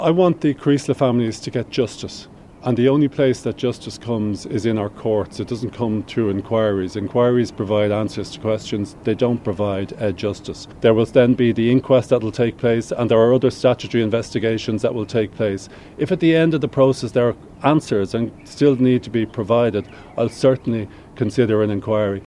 He says they deserve answers, but repeated his belief that other processes must be completed first………………